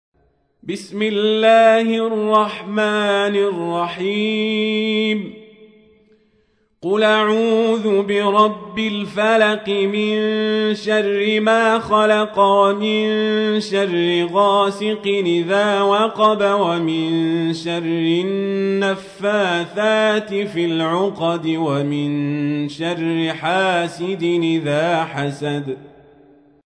تحميل : 113. سورة الفلق / القارئ القزابري / القرآن الكريم / موقع يا حسين